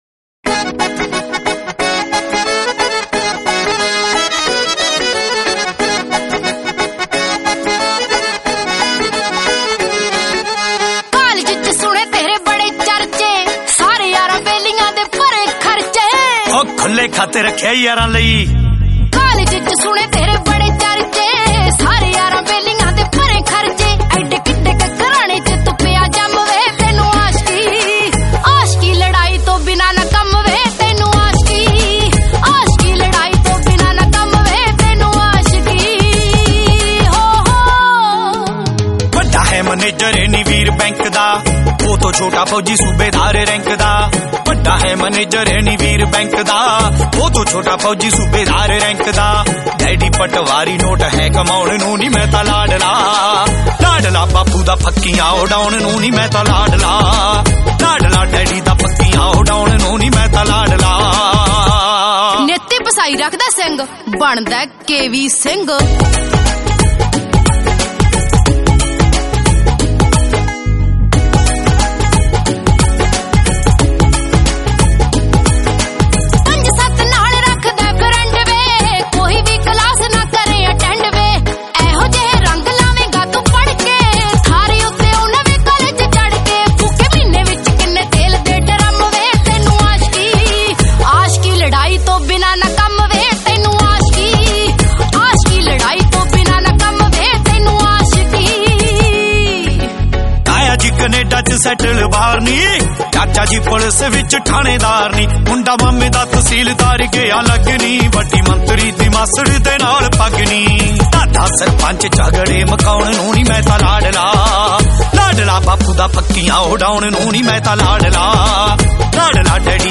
Punjabi Bhangra MP3 Songs